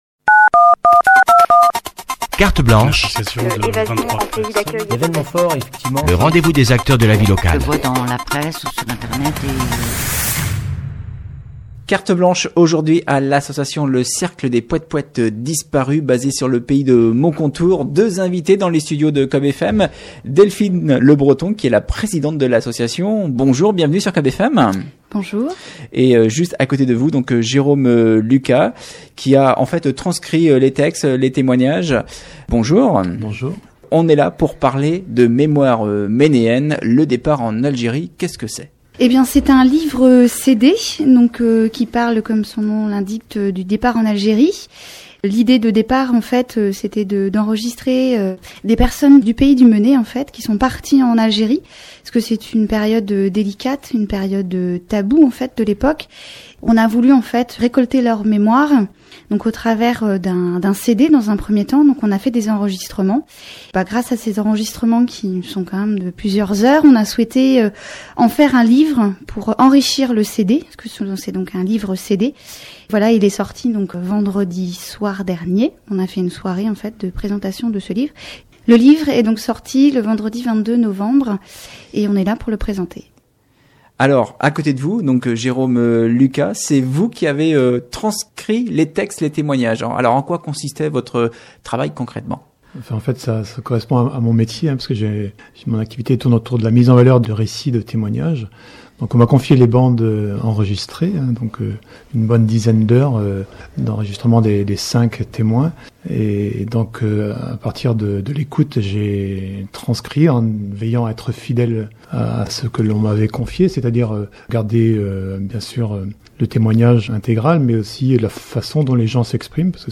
Des témoins habitant le pays du Méné racontent leur départ en Algérie à la fin des années 50